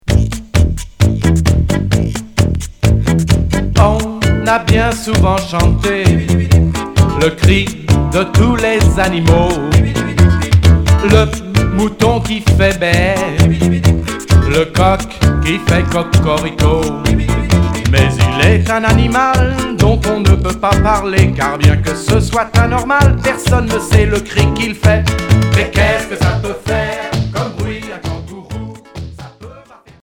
Rock humoristique Sixième 45t retour à l'accueil